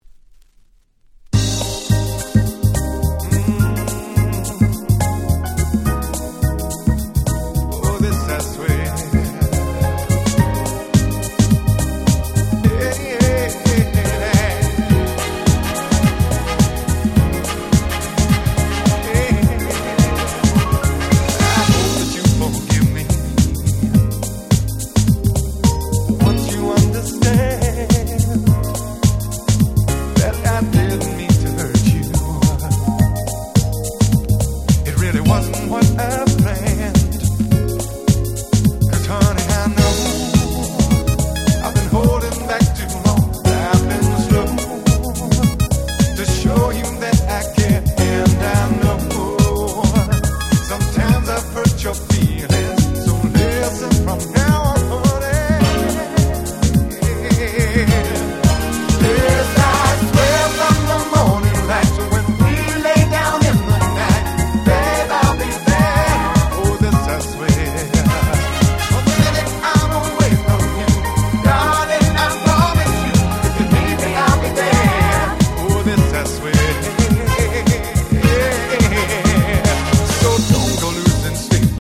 93' Super Nice UK Soul / R&B !!
爽快なメロディーが堪りません！